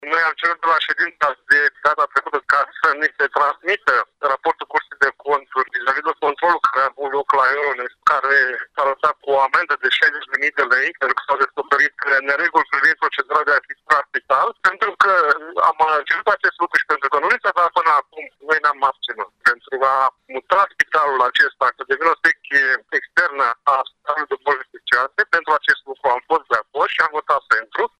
Preşedintele grupului consilierilor liberali, Romeo Vatră a subliniat că a votat doar o pentru parte din proiecte, ținând cont de faptul că sunt numeroase nereguli semnalate la procedura de achiziţie a spitalului.